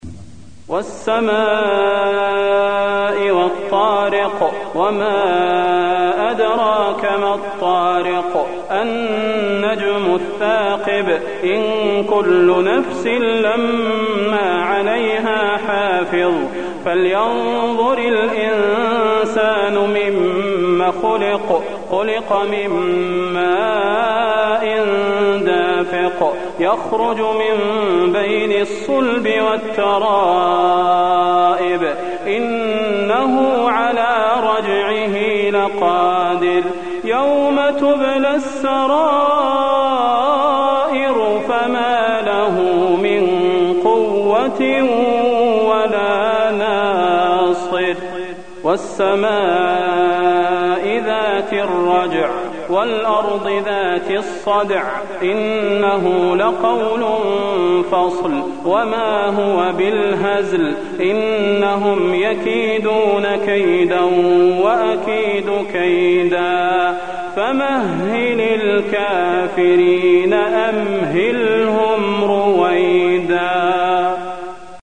المكان: المسجد النبوي الطارق